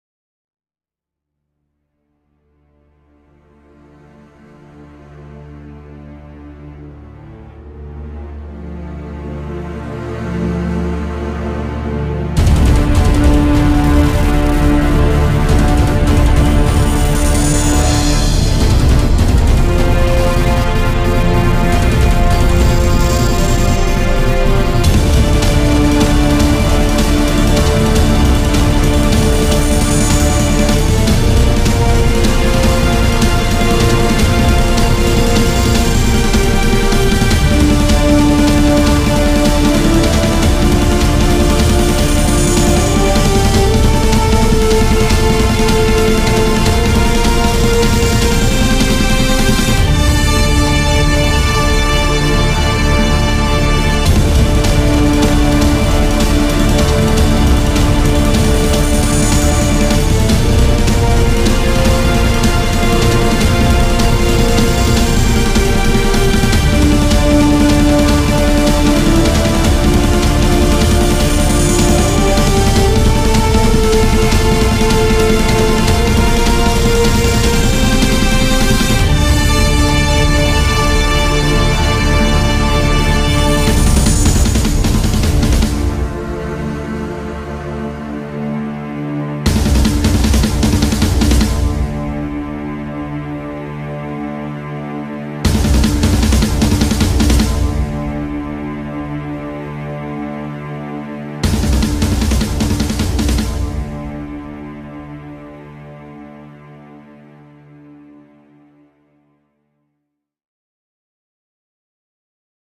tema dizi müziği, heyecan gerilim aksiyon fon müzik.